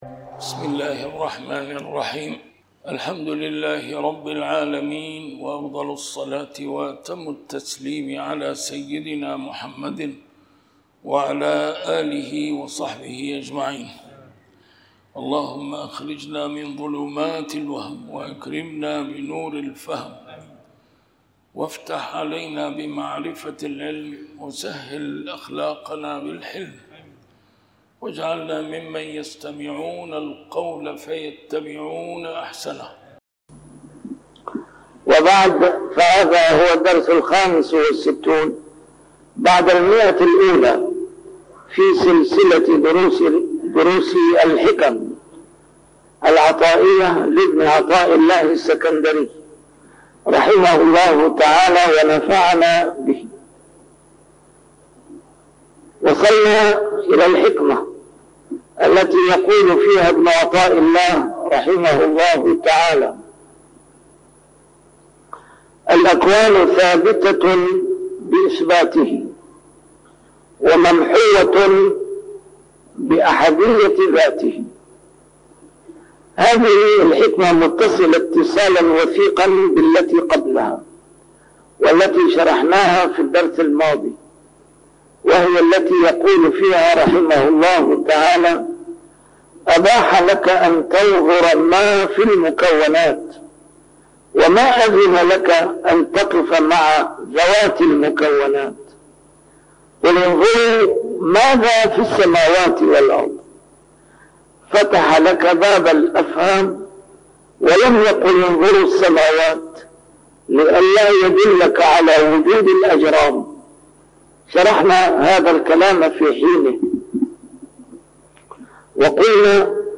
A MARTYR SCHOLAR: IMAM MUHAMMAD SAEED RAMADAN AL-BOUTI - الدروس العلمية - شرح الحكم العطائية - الدرس رقم 165 شرح الحكمة 141